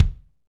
KIK FNK K00L.wav